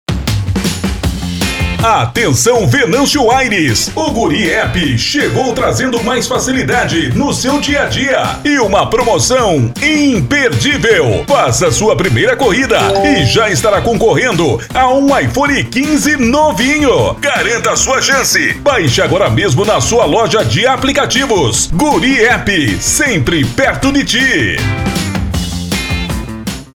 ENTONAÇÃO JOVEM: